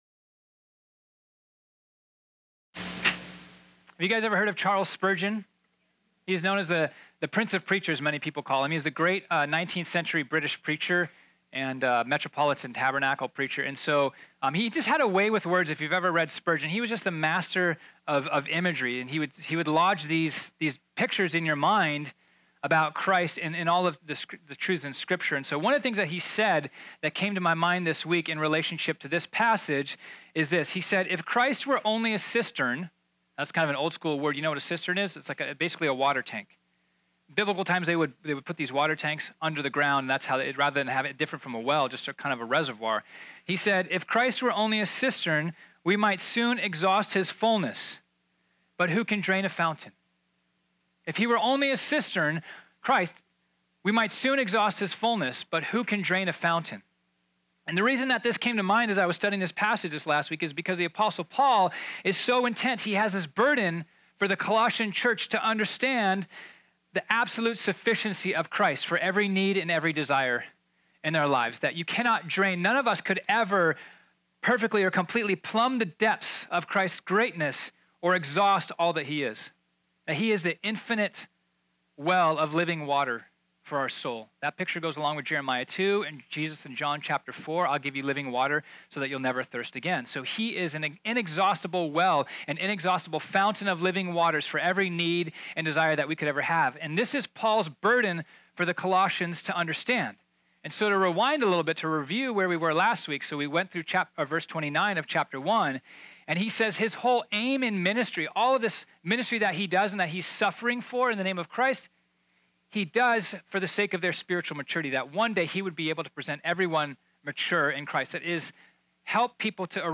This sermon was originally preached on Sunday, October 7, 2018.